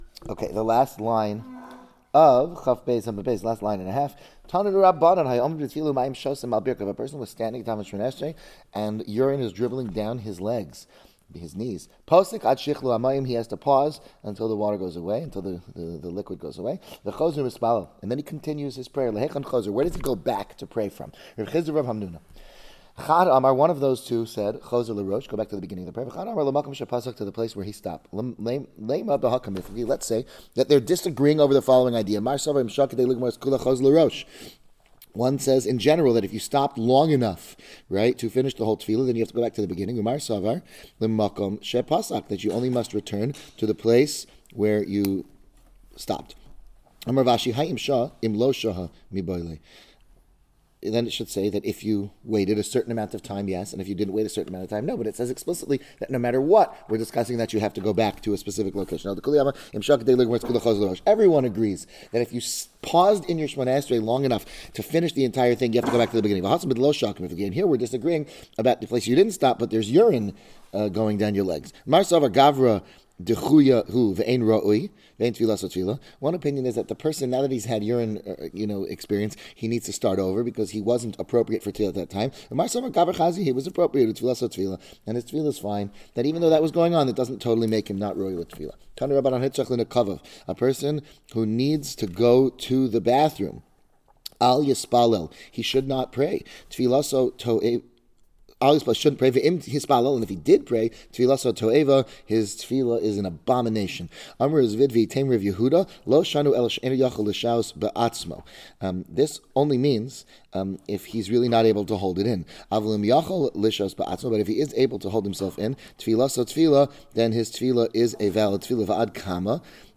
Maseches Berachos: Daf Yomi Breakneck – 7 Blatt A Week In 2 Hours Shiur